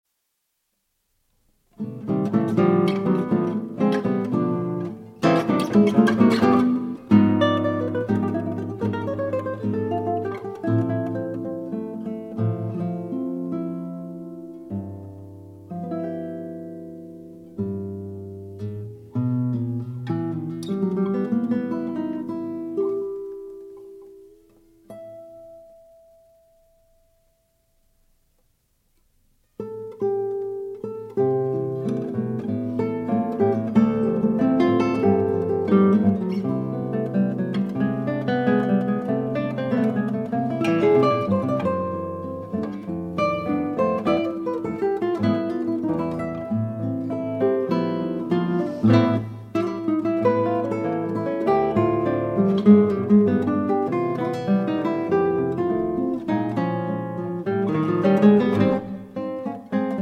an album of all instrumental music
guitar